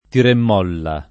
tiremmolla [ tiremm 0 lla ]